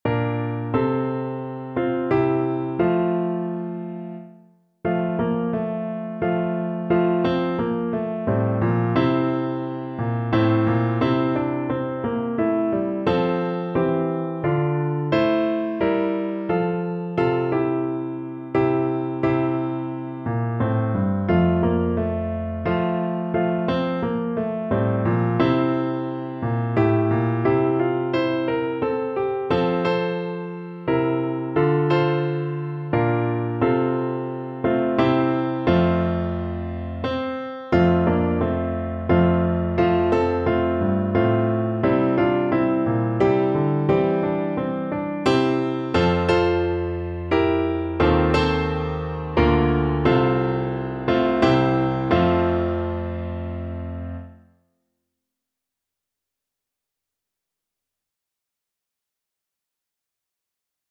Lively ( = c.120)